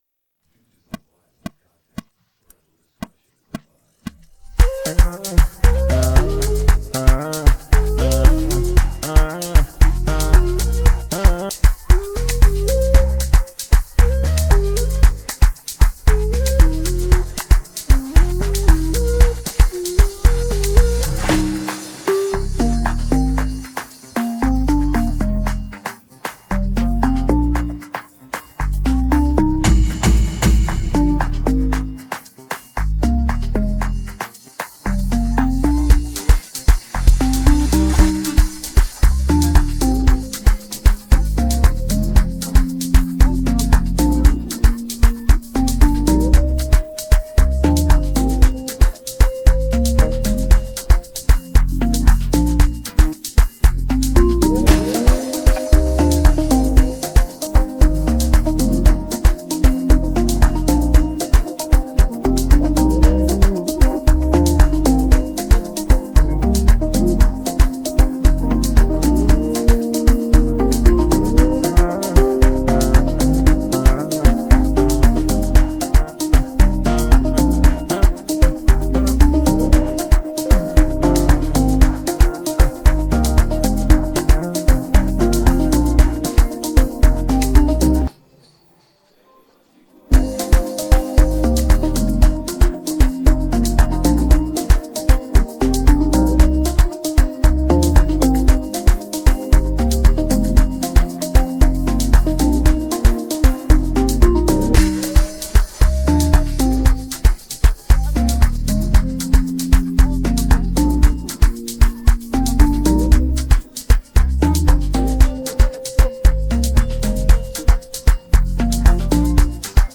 AfrobeatsAmapaino